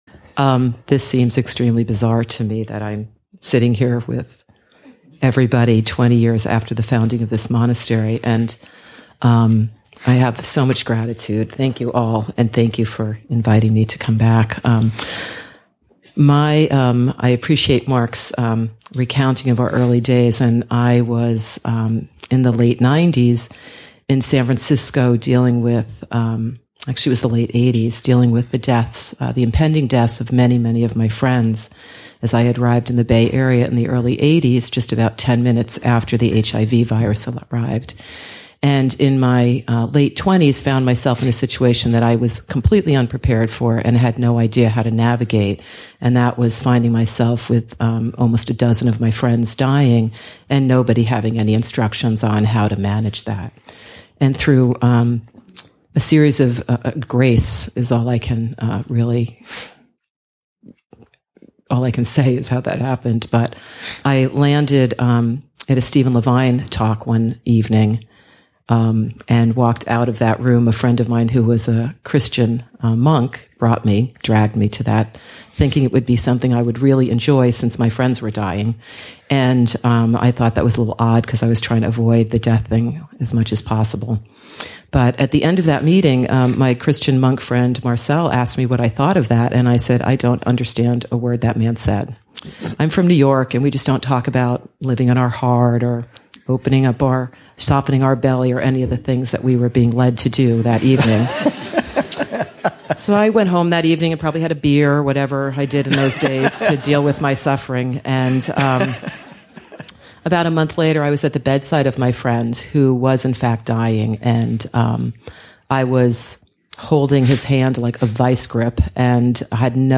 Teachers / Lay teachers / Stephen Levine 1 excerpt, 3:37 total duration Abhayagiri's 20th Anniversary , Session 8 – Jun. 4, 2016 Download audio (3:37) 1.